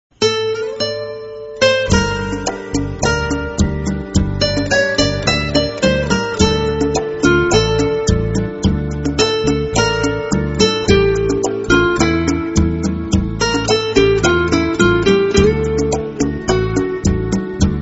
1 – نغمة عزف بيانو (piano_tone)